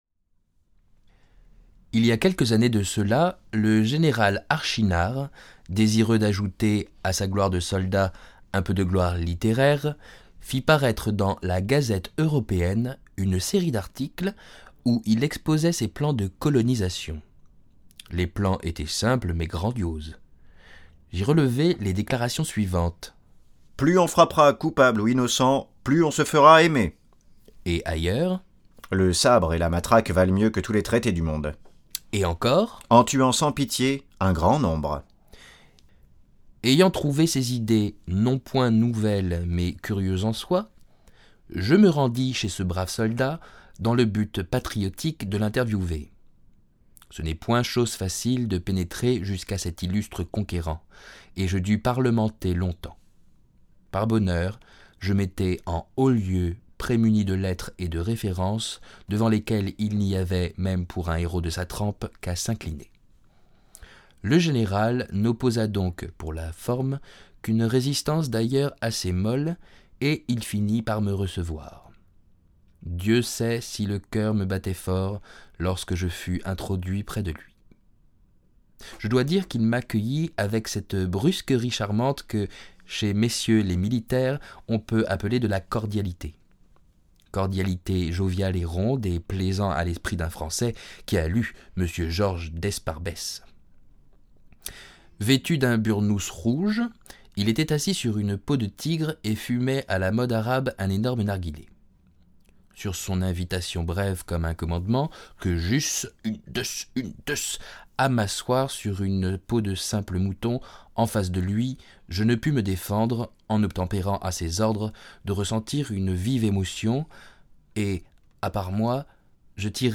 Extrait lu